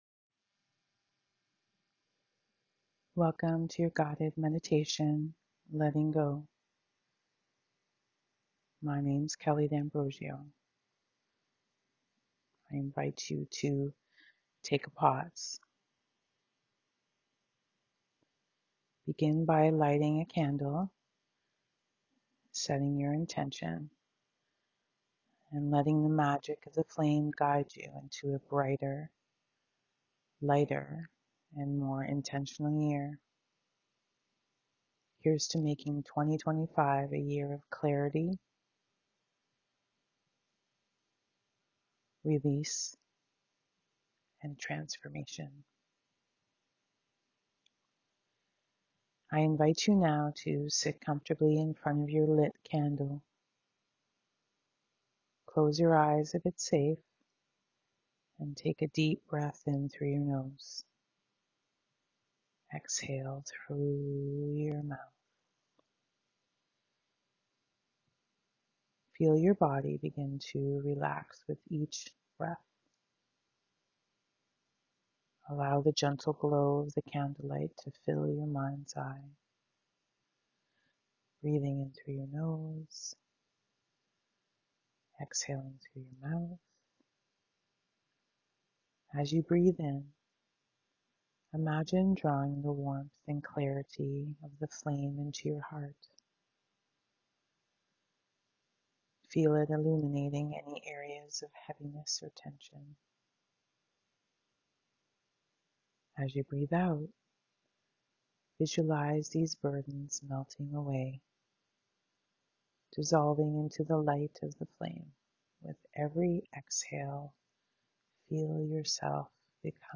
Guided Meditation "Letting Go"